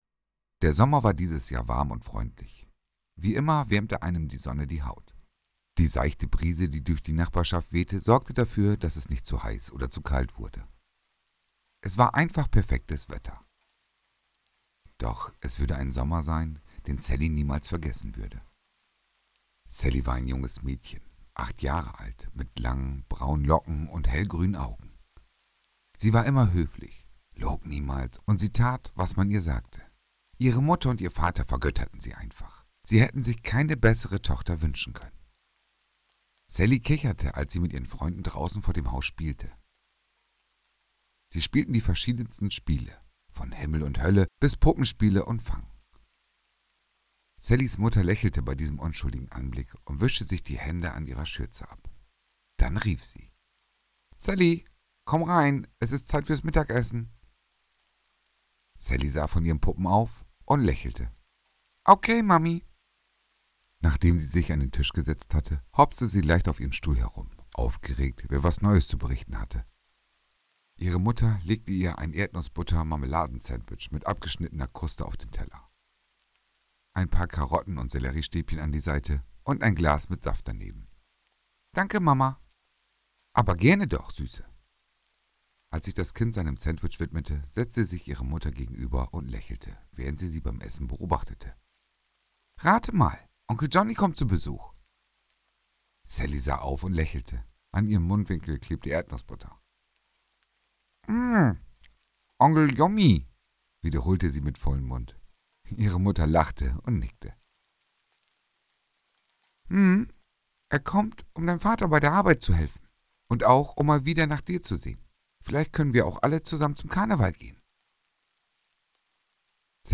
Hallo Gruselfreunde Dieses ist eine gruseliger Vorlesepodcast!!!